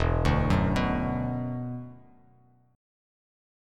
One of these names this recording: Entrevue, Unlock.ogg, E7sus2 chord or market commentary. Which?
E7sus2 chord